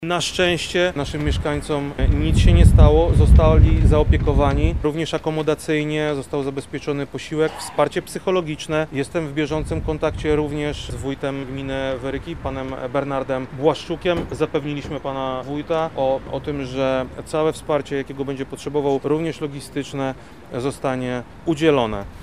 – mówi Krzysztof Komorski, wojewoda lubelski